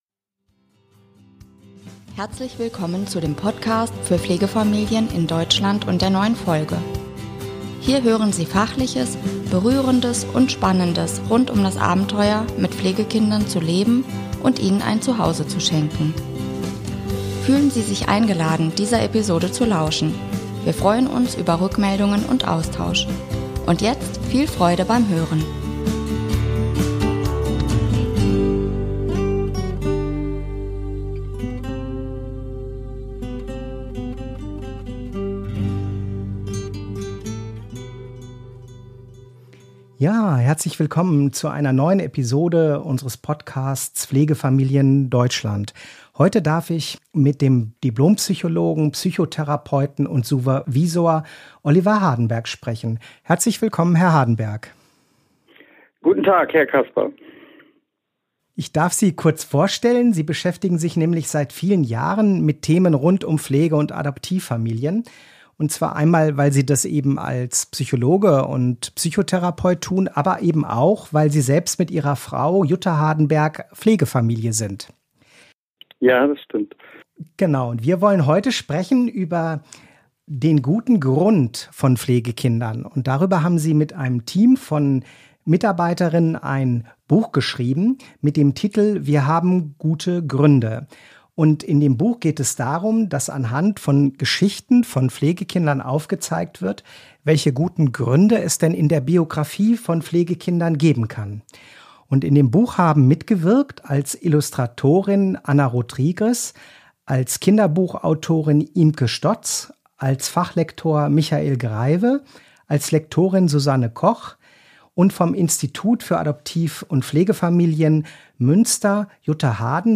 In den Podcast ist sozusagen eine kleine Lesung integriert, so dass unsere Hörer*innen sich einen Eindruck zu den Pflegekindergeschichten machen können.